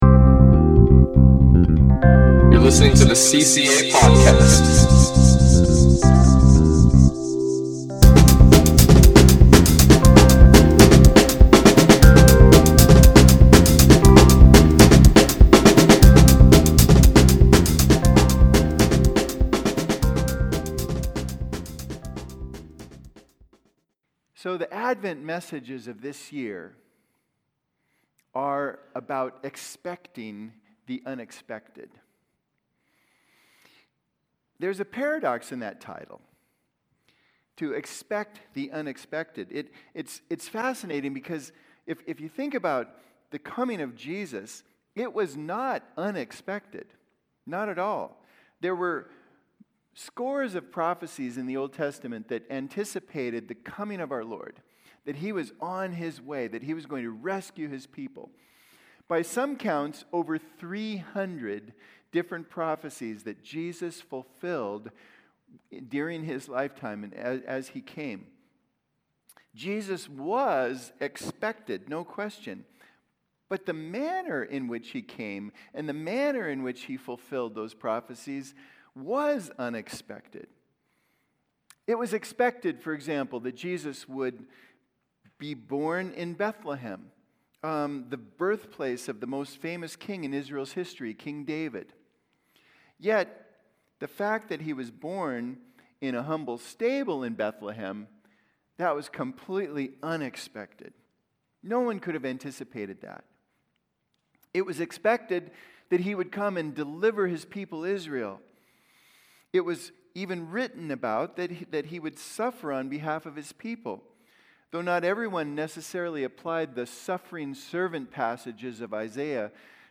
The Advent sermon series